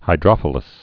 (hī-drŏfə-ləs)